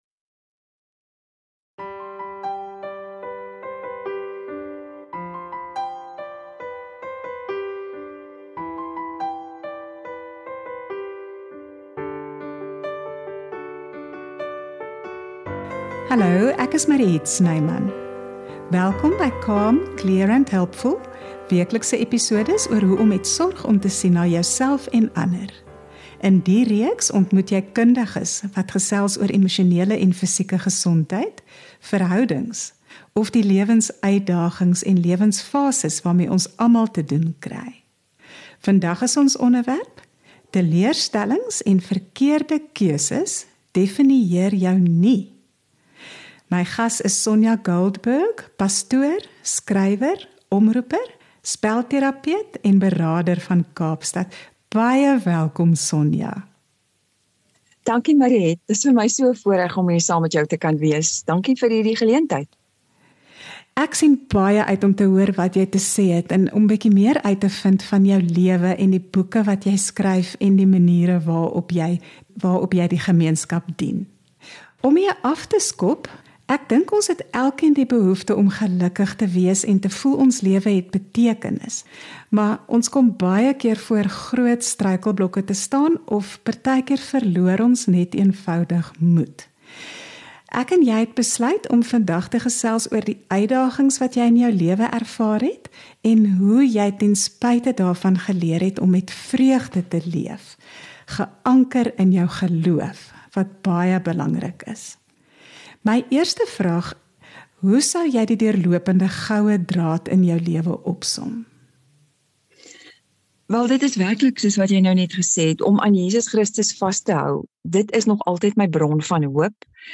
Join us each Tuesday for fresh insights, practical know-how, and for conversations from the heart.